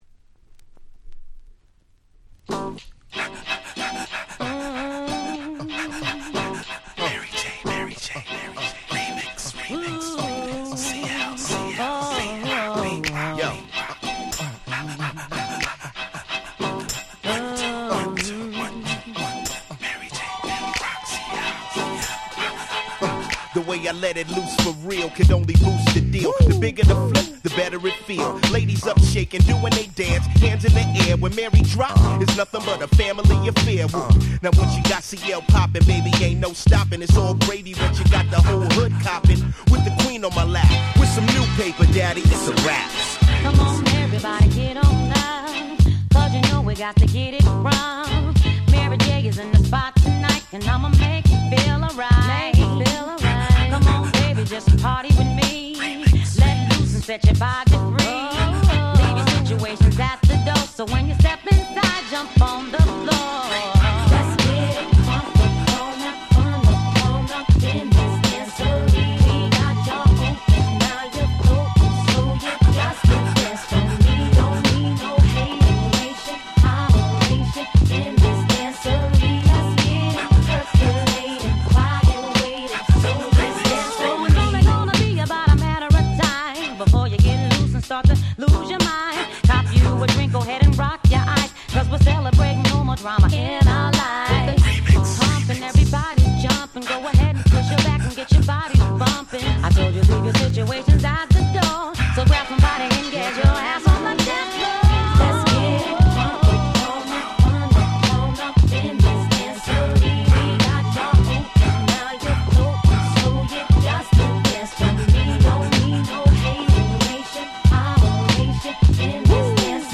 【Media】Vinyl 12'' Single
01' Nice Remix !!